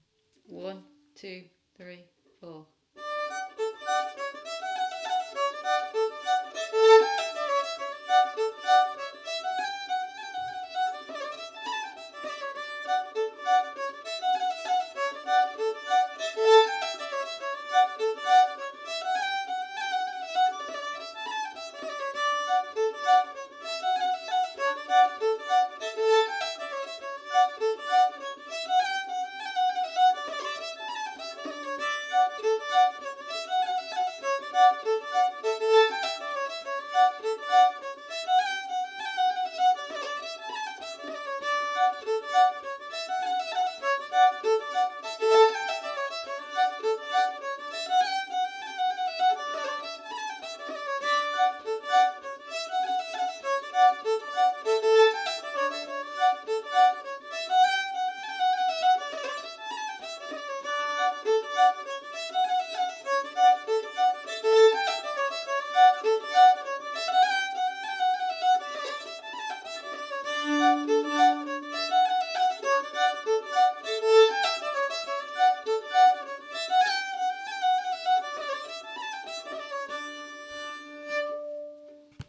B Part Only